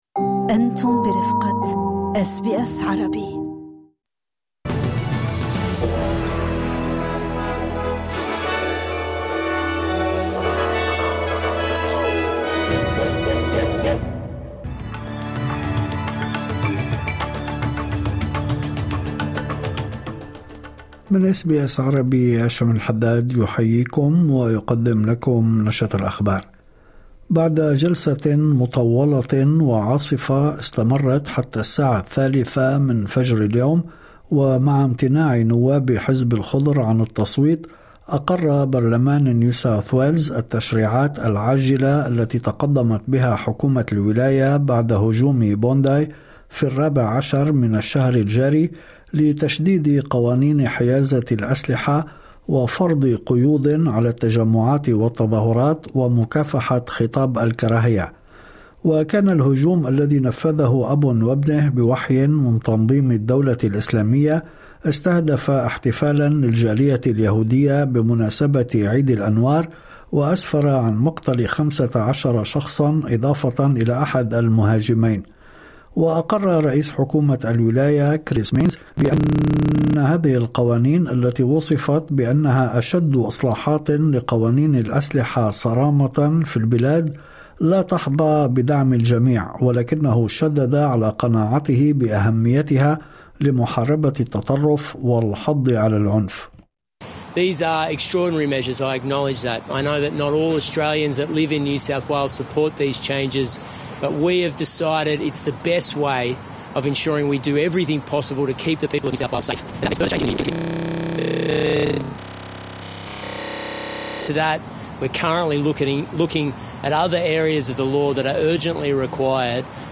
نشرة أخبار الظهيرة 24/12/2025